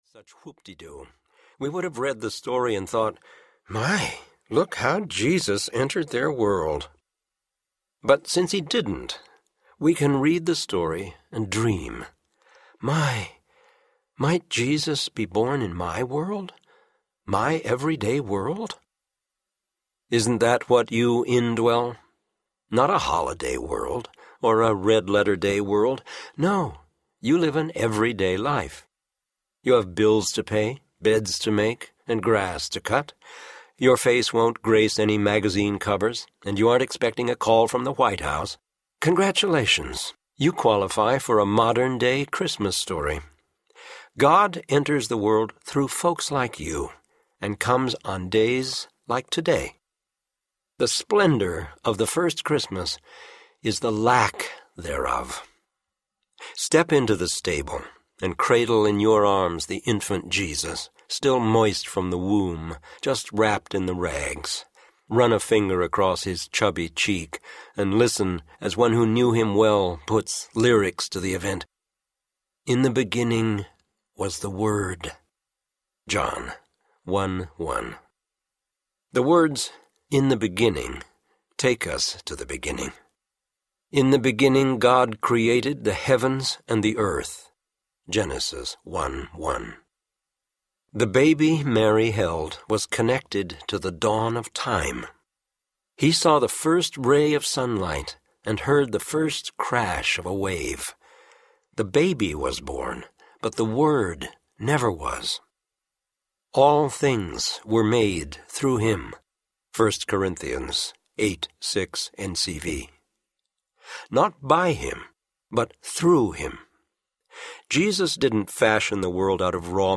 God’s Story, Your Story Audiobook
Narrator